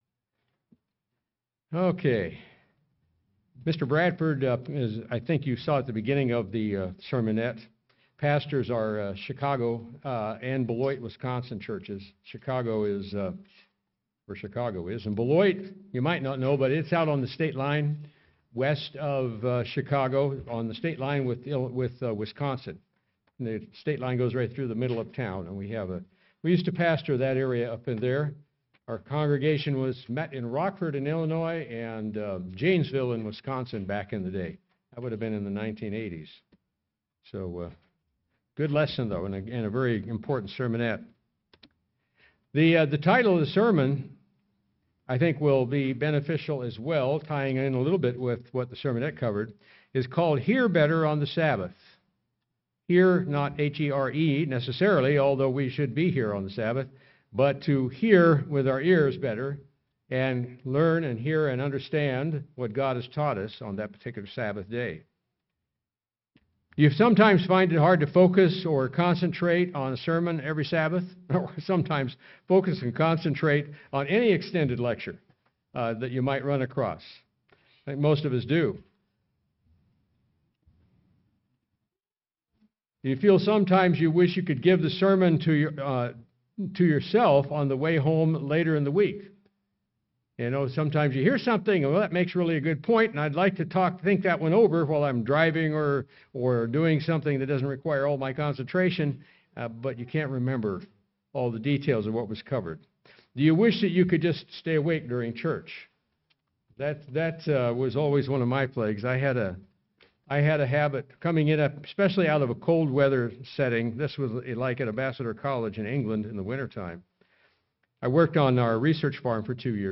Sermons
Given in Sioux Falls, SD Watertown, SD